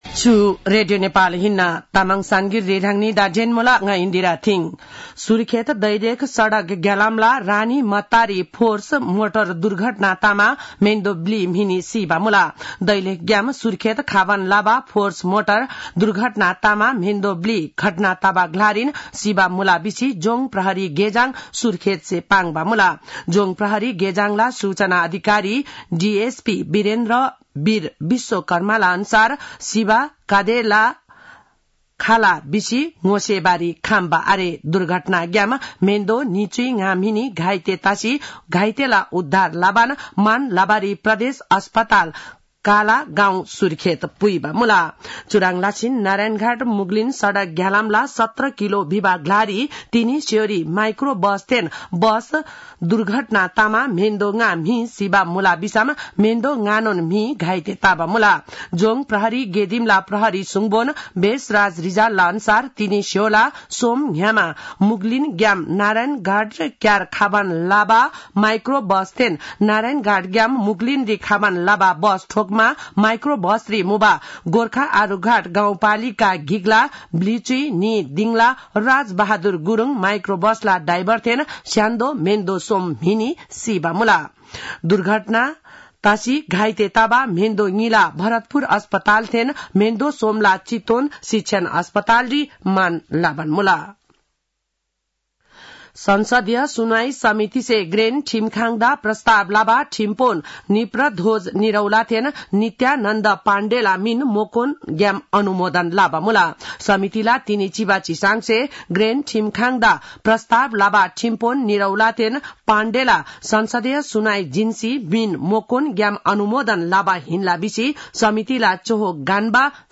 तामाङ भाषाको समाचार : २४ कार्तिक , २०८१